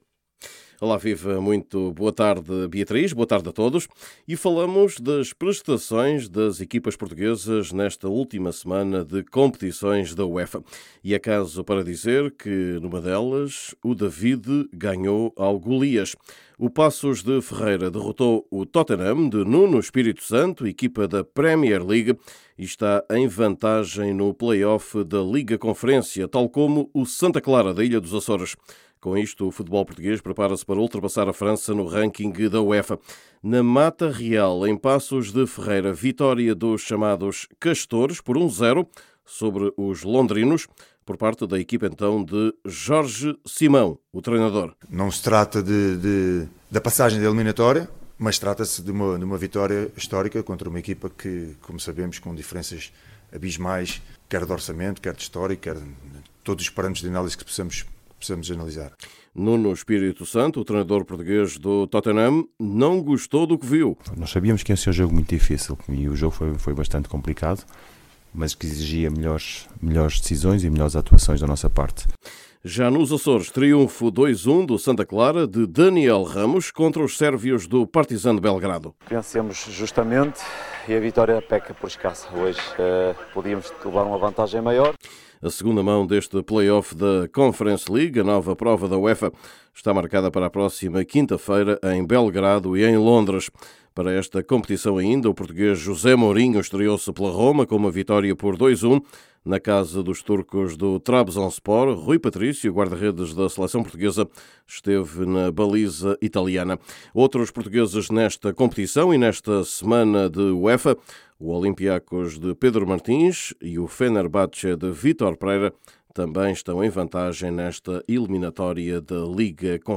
Paços de Ferreira fez história ao derrotar o Tottenham, da Premier League. Neste boletim, ouça os treinadores, em particular o português dos ‘spurs’.